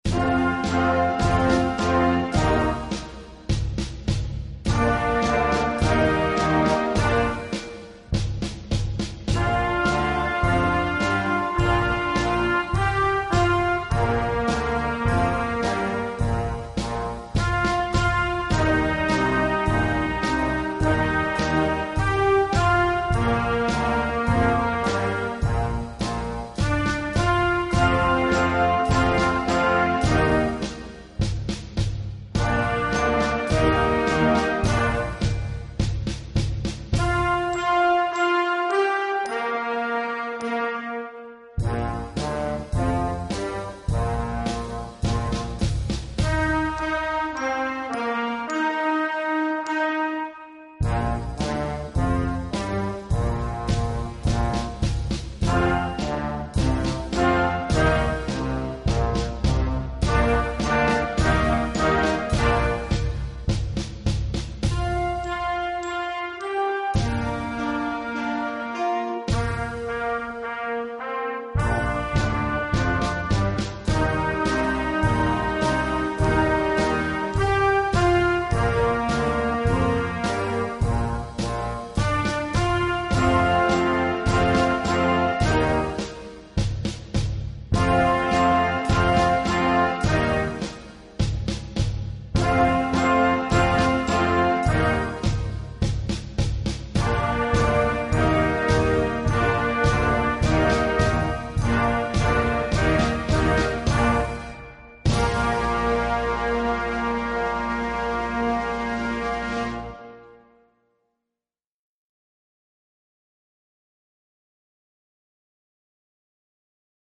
Gattung: Blasmusik für Jugendkapelle
Besetzung: Blasorchester